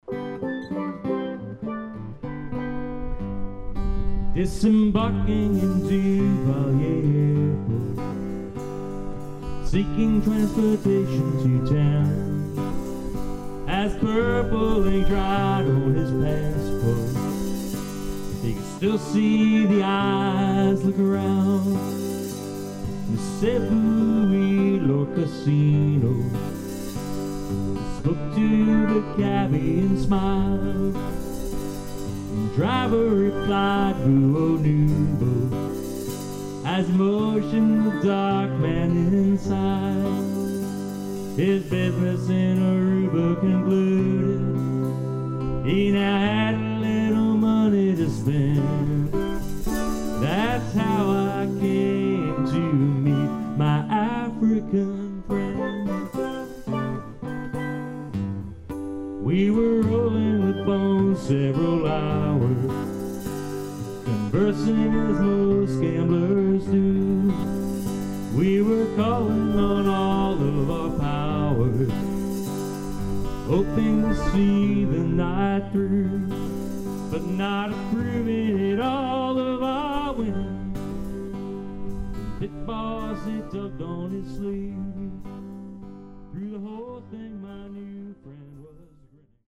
Vocal Demos Instrumental Demos